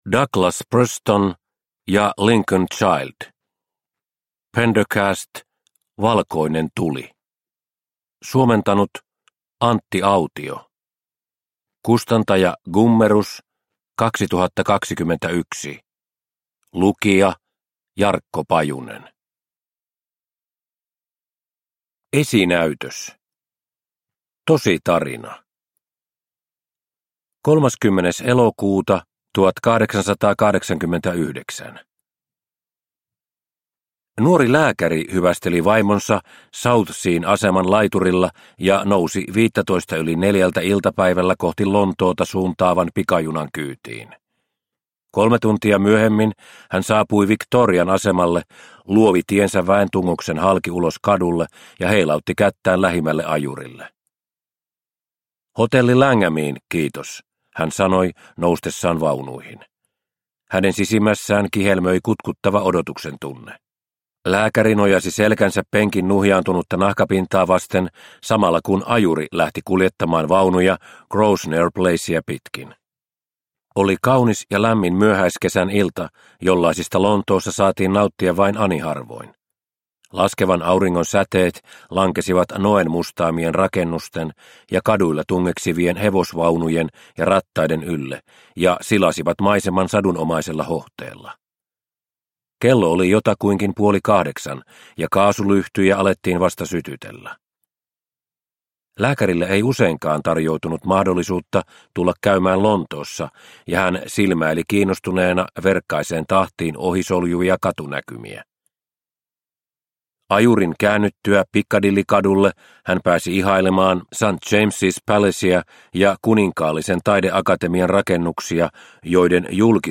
Valkoinen tuli – Ljudbok – Laddas ner